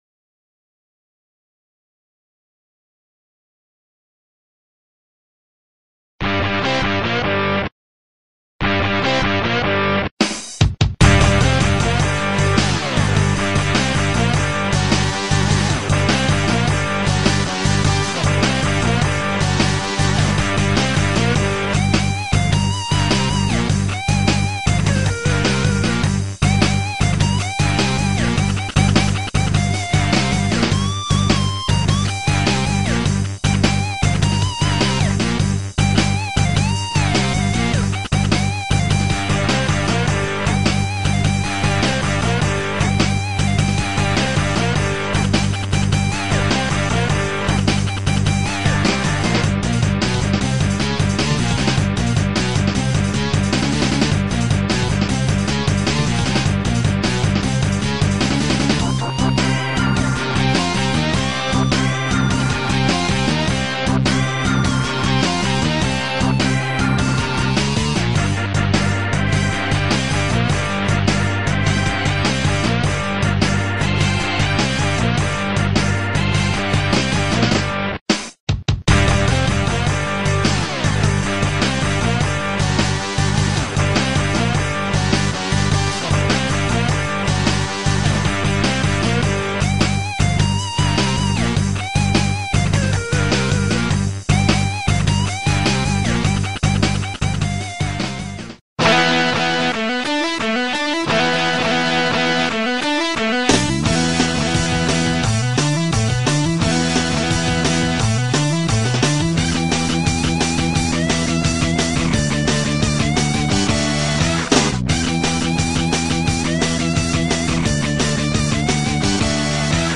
Bem… fique com trechos da trilha sonora…